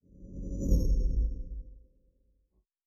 pgs/Assets/Audio/Sci-Fi Sounds/Movement/Fly By 06_1.wav at 7452e70b8c5ad2f7daae623e1a952eb18c9caab4
Fly By 06_1.wav